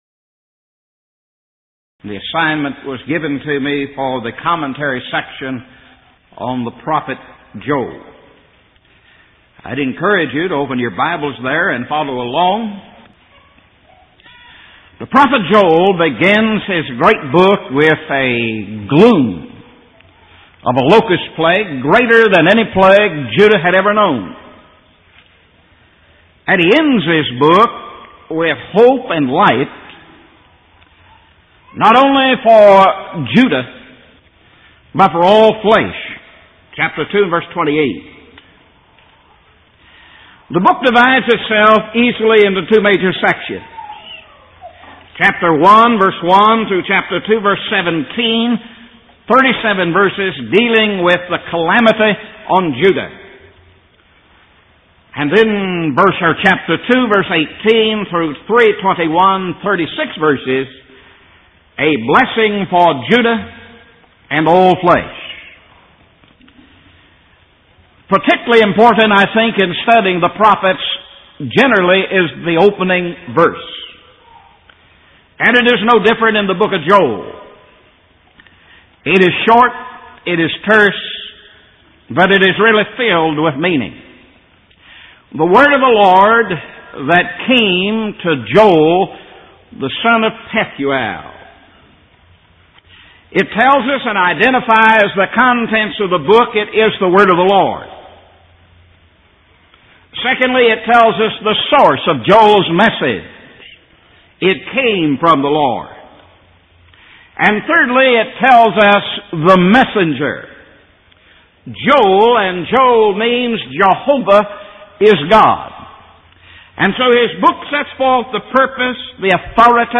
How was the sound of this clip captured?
Event: 1990 Power Lectures